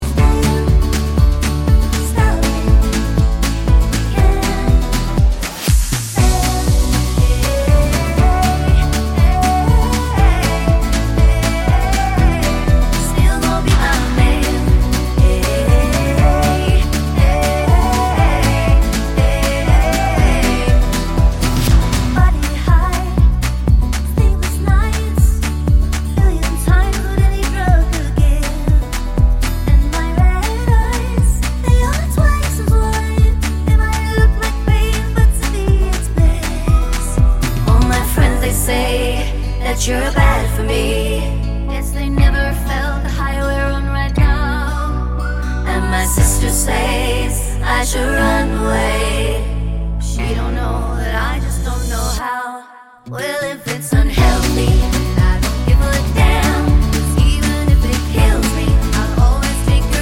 Duet Version Pop